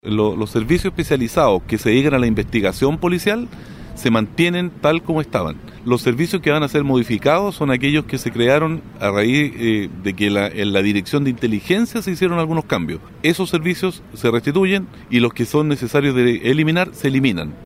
Consultado por Radio Bío Bío, Soto descartó la eliminación de unidades especializadas, en medio de la reestructuración que sufre la institución, como el OS9 y de la Sección de Investigación Policial (SIP).
Las declaraciones fueron emitidas tras su participación en el cambio de mando regional, instancia donde -ante unos 400 efectivos policiales- asumió como jefe de de la Octava Zona de Carabineros el coronel Rodrigo Medina, quien ejercía como prefecto de Concepción.